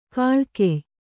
♪ kāḷkey